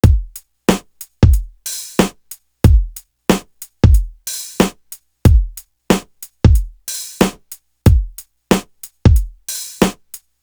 Worst To Worst Drum.wav